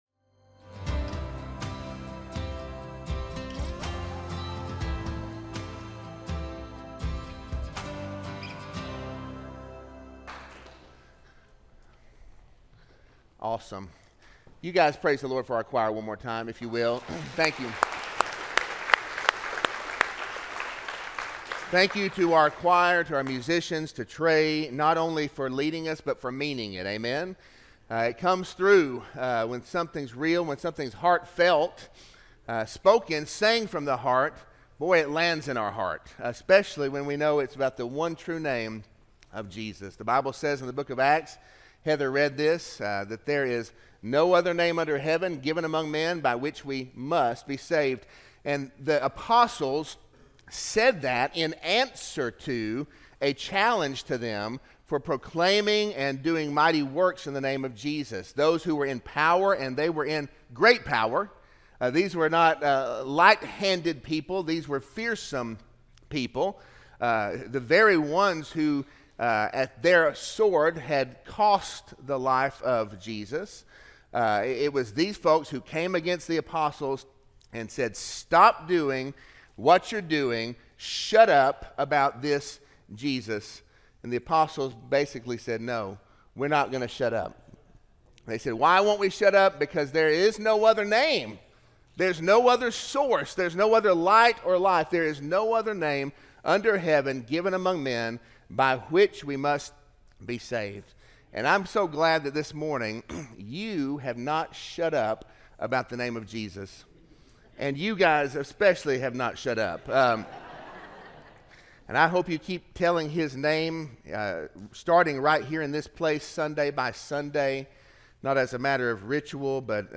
Sermon-11-3-24-audio-from-video.mp3